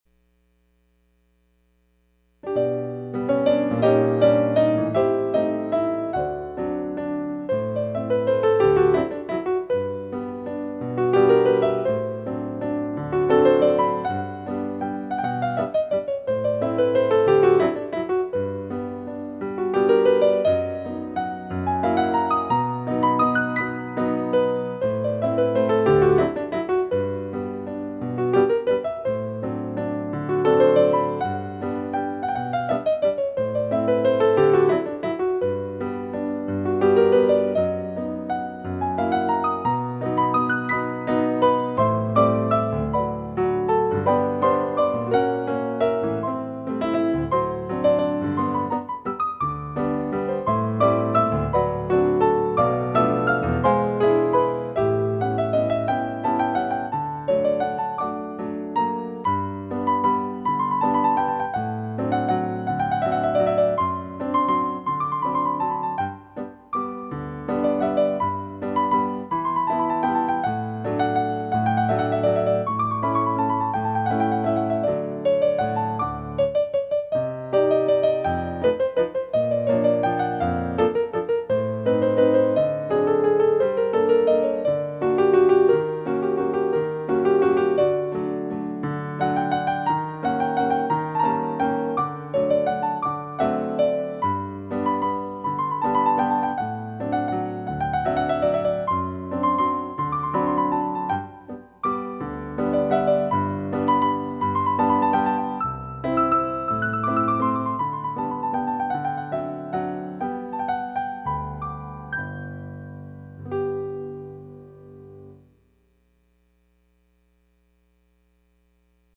Music for Pirouettes